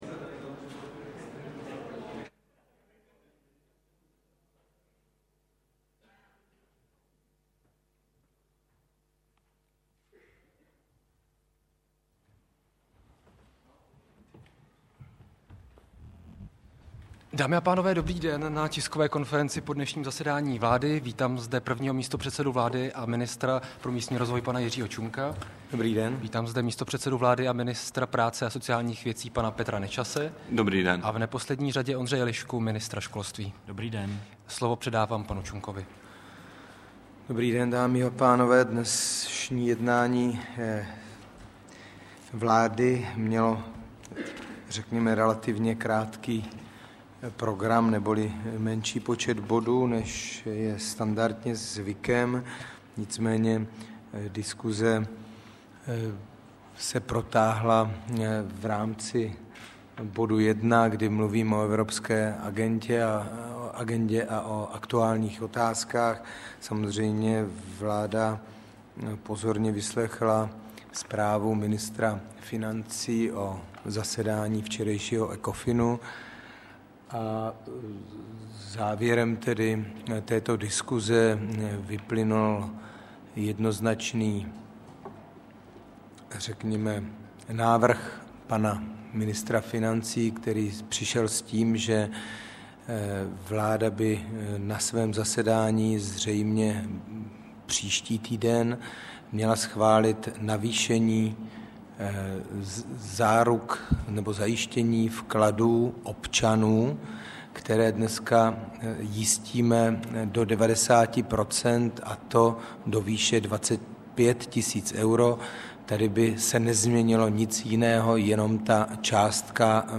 Tisková konference po jednání vlády ČR 8. října 2008